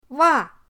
wa4.mp3